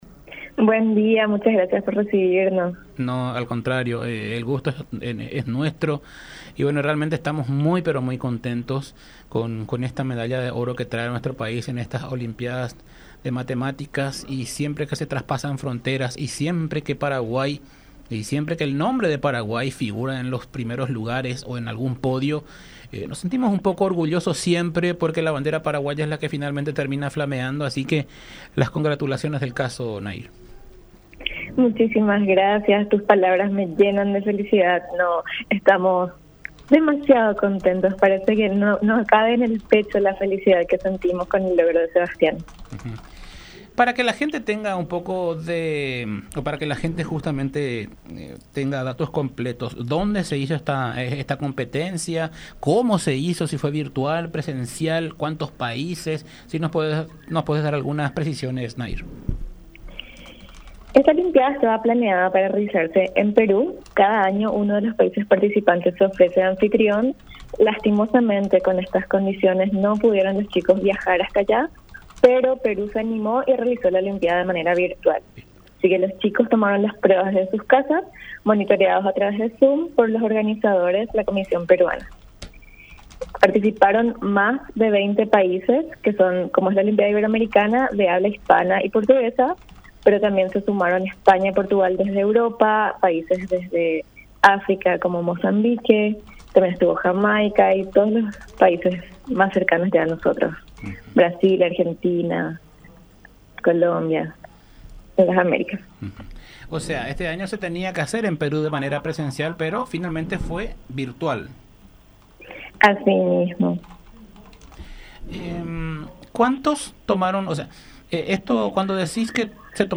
todo un orgullo para nosotros y para el país” expresó en dialogo con La Unión R800 AM.